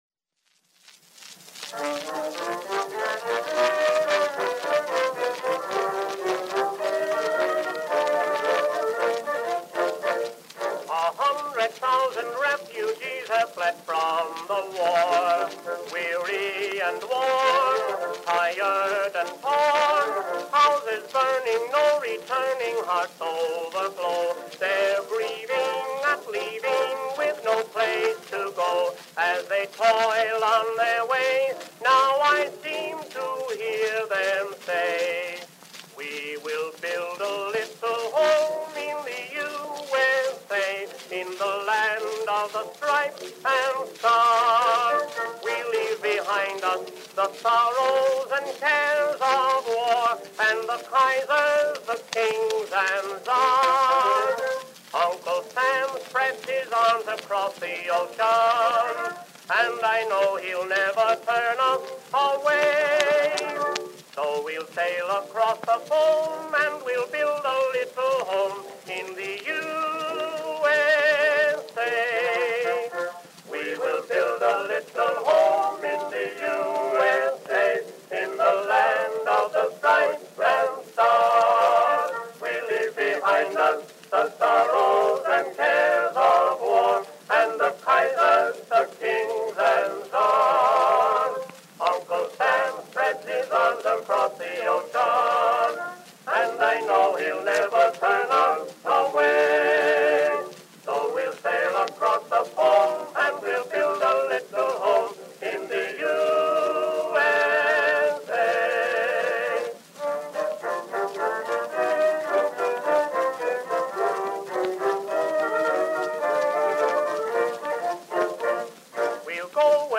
Our collection is comprised of wax cylinder donations from many sources, and with the digitization process being fully automated, not all listed contents have been confirmed.